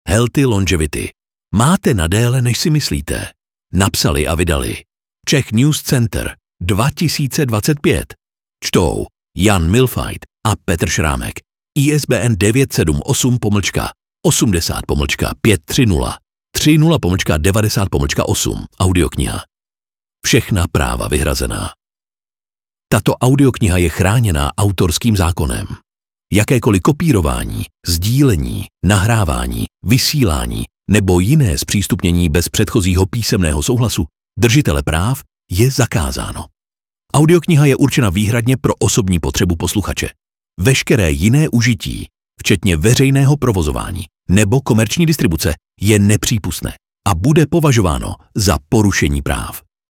HEALTHY LONGEVITY audiokniha
Ukázka z knihy